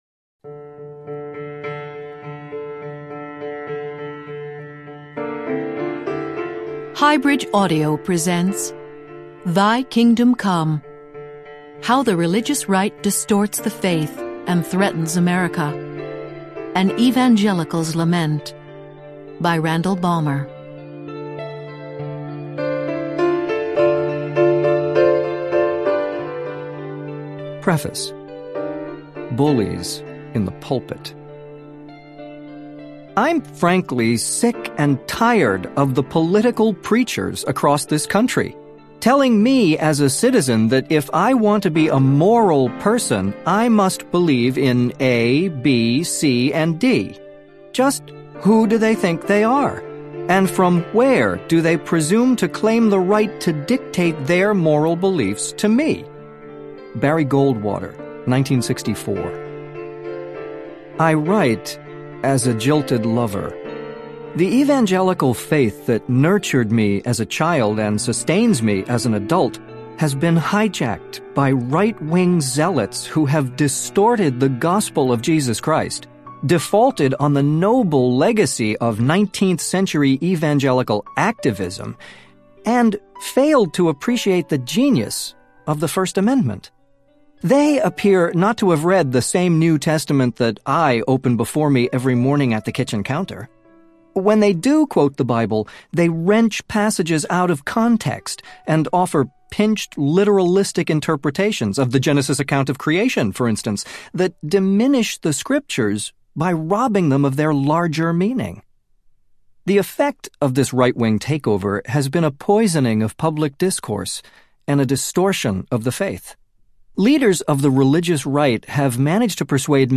Thy Kingdom Come Audiobook
Narrator